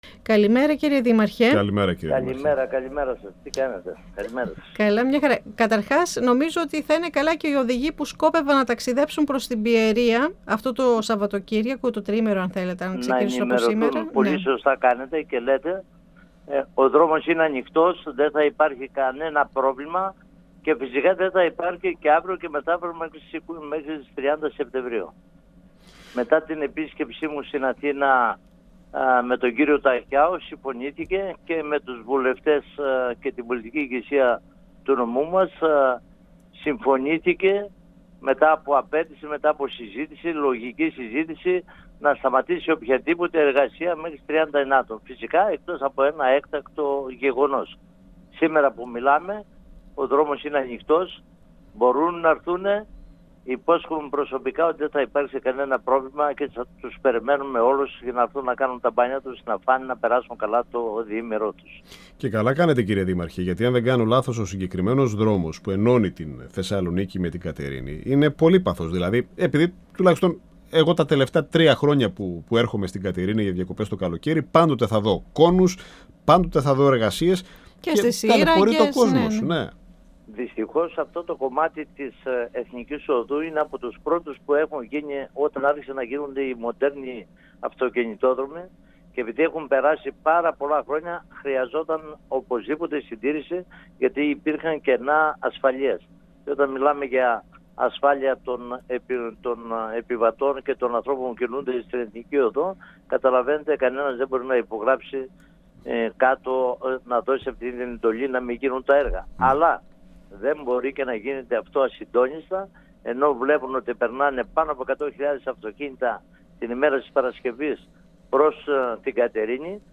Στον απόηχο της έντονης δυσφορίας και της κατακραυγής των ταξιδιωτών , λόγω της αυξημένης ταλαιπωρίας χιλιάδων πολιτών την περασμένη  Κυριακή 6 Ιουλίου, εξαιτίας των κυκλοφοριακών ρυθμίσεων που εφαρμόστηκαν στην ΠΑΘΕ από την παραχωρησιούχο εταιρεία «Αυτοκινητόδρομος Αιγαίου» αναφέρθηκε ο Δήμαρχος Κατερίνης Γιάννης Ντούμος, μιλώντας στην εκπομπή «Αίθουσα Σύνταξης» του  102FM της ΕΡΤ3.